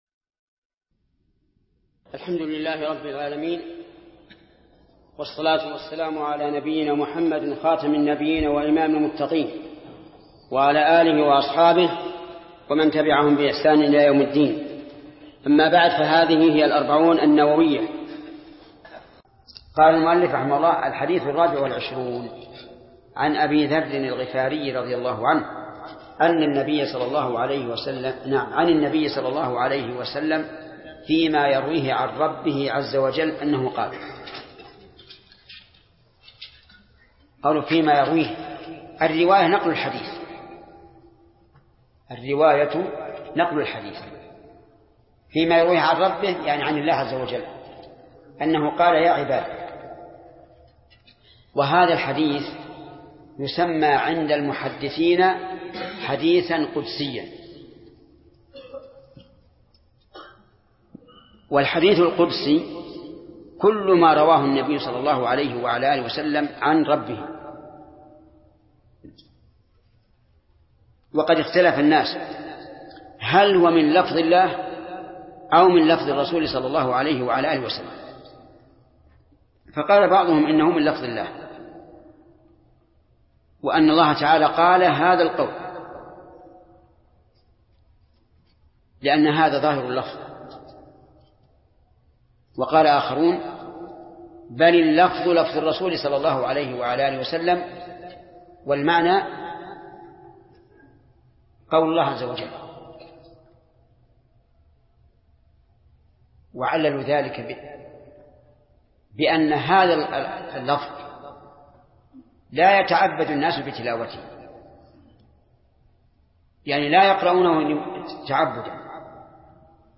الدرس الثامن عشر : من قوله: الحديث الرابع والعشرون، إلى: نهاية الحديث الرابع والعشرون.